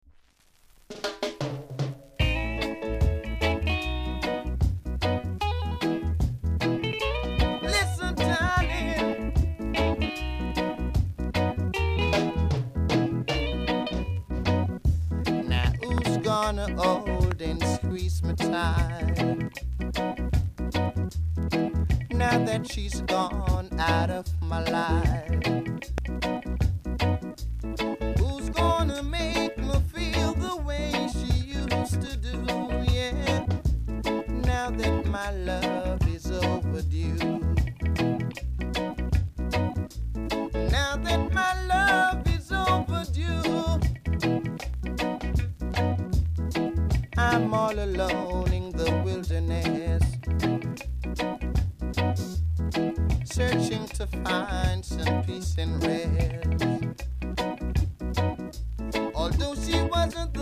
※序盤に数回パチノイズあります。